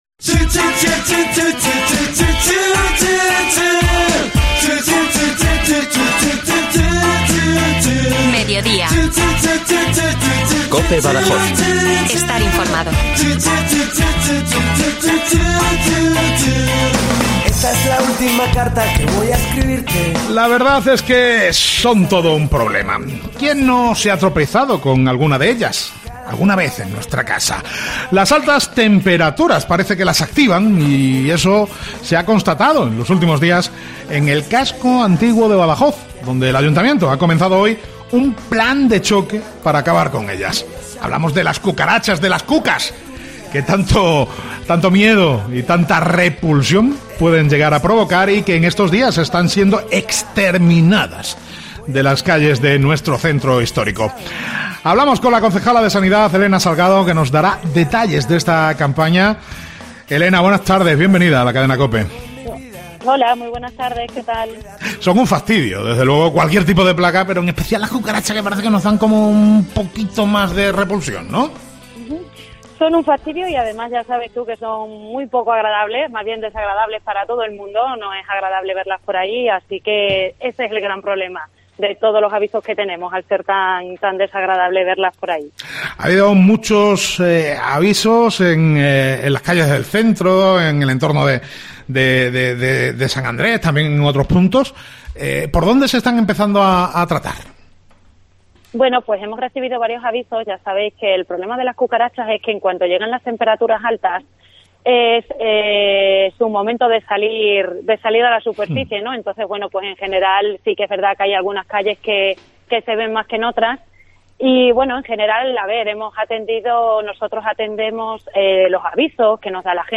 Una campaña extraordinaria de fumigación que nos daba a conocer, en COPE, la Concejala de Sanidad, Elena Salgado en una entrevista en Mediodía COPE Badajoz en la que señalaba que también se está actuando contra las ratas.